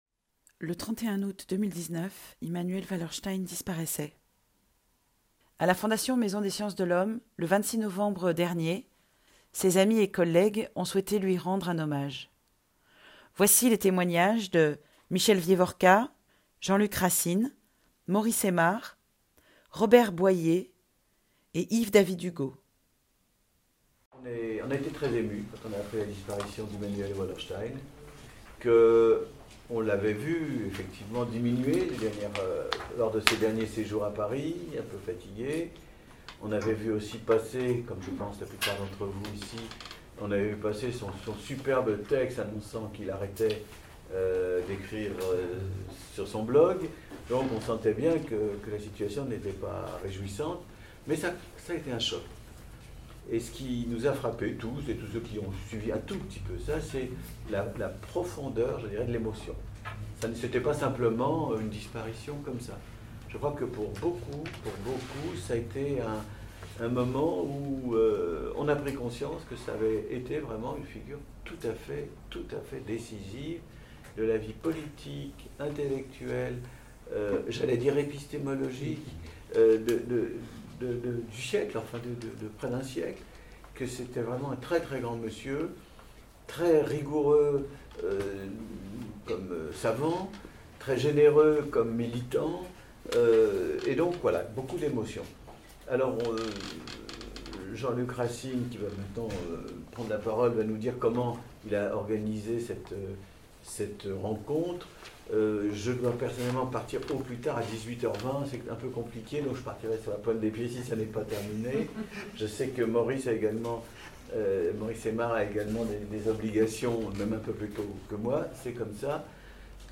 L’Association des Amis de la FMSH, en partenariat avec la Fondation Maison des sciences de l'homme, a organisé le 26 novembre 2019, une rencontre autour de l’apport d’Immanuel Wallerstein, figure intellectuelle de premier plan, théoricien des « systèmes-monde » et observateur engagé de l’altermondialisme.
Voici quelques témoignages de ses amis et collègues de la Fondation Maison des sciences de l'homme.